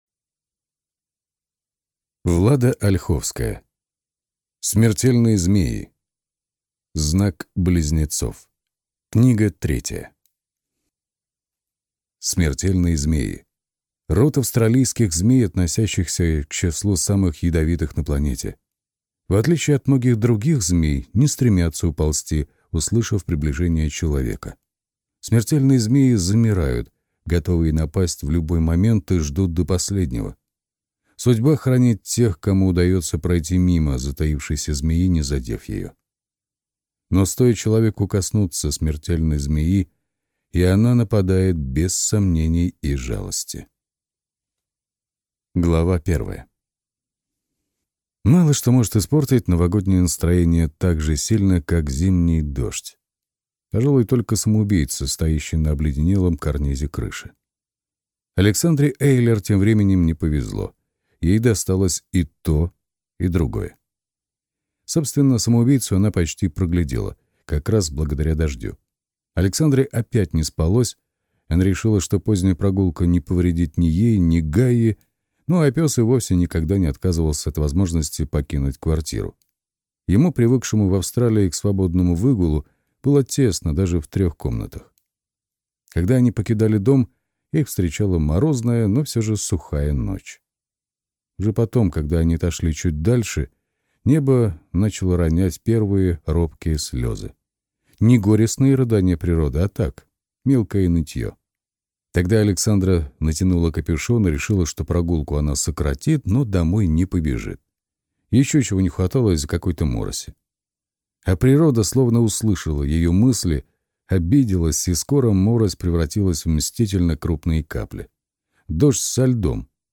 Аудиокнига Смертельные змеи | Библиотека аудиокниг